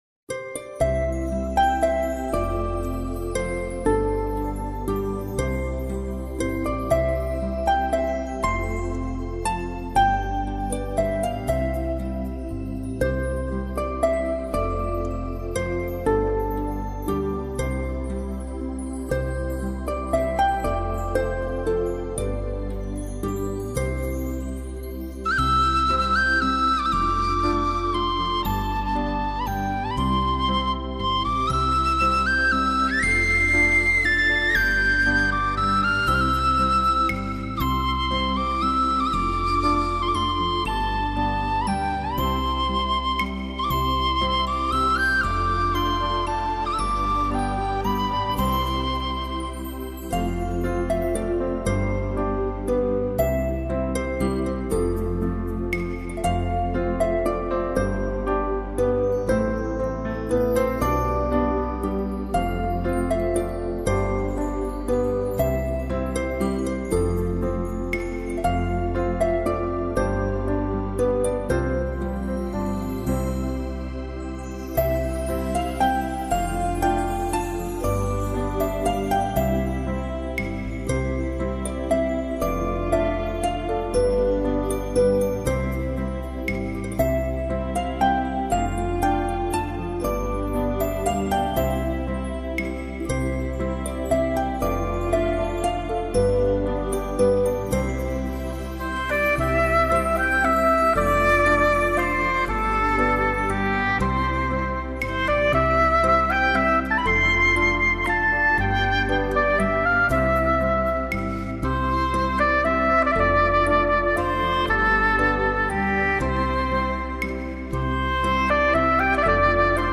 这首曲子可谓是灰常空灵